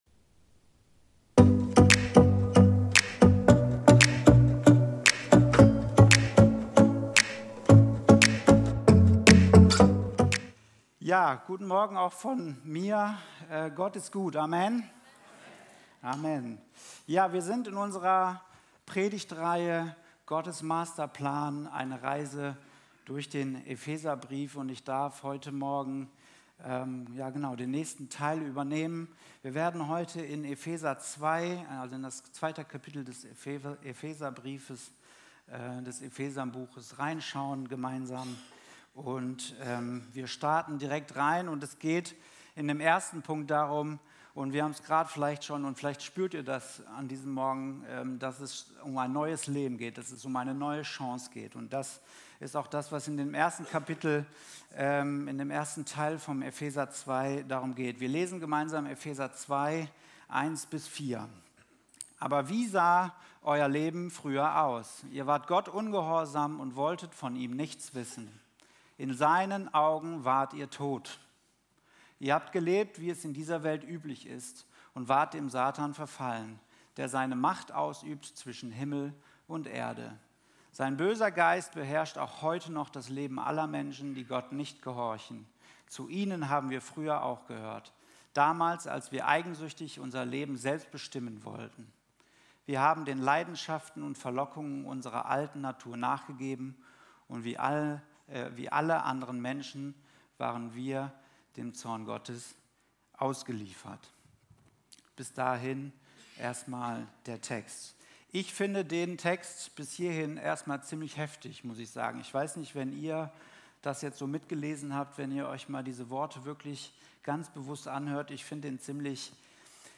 Video und MP3 Predigten
Kategorie: Sonntaggottesdienst